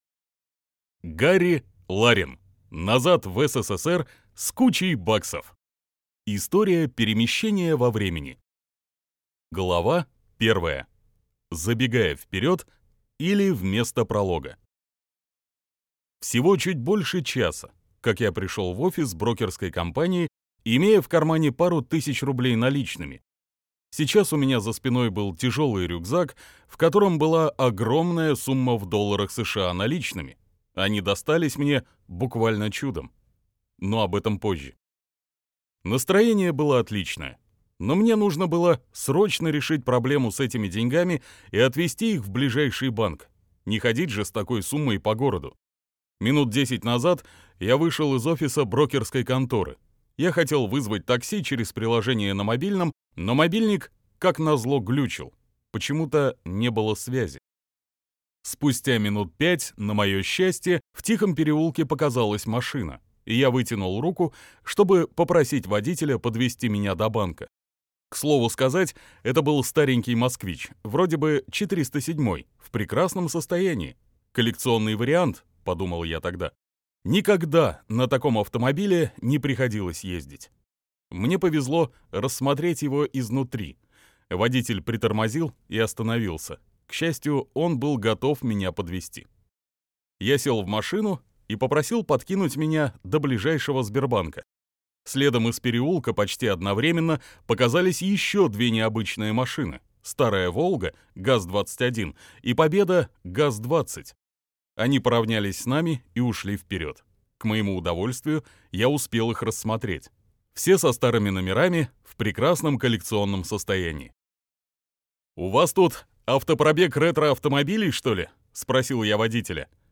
Аудиокнига Назад в СССР с кучей баксов. История перемещения во времени | Библиотека аудиокниг